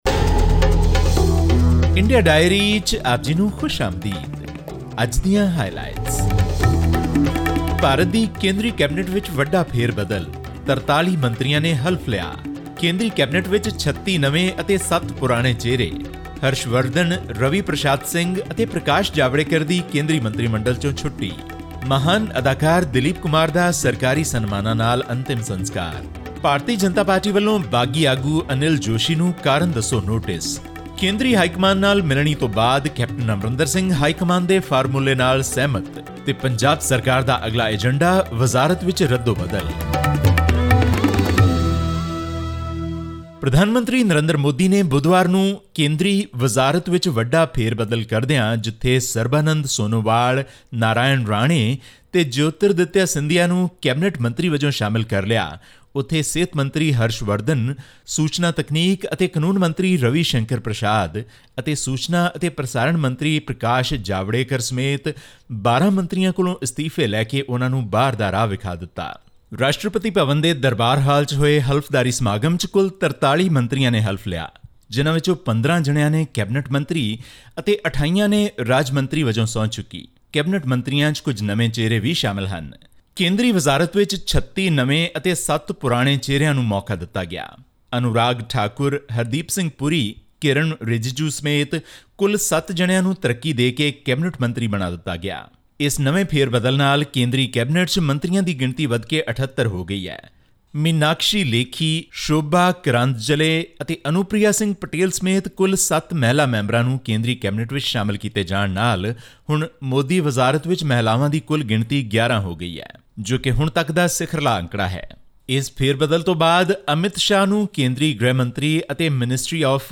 Prime Minister Narendra Modi has appointed new cabinet ministers for health, IT and oil in a dramatic reshuffle to improve his government’s image amid criticism of its handling of the COVID-19 pandemic. This and more in our weekly news bulletin from India.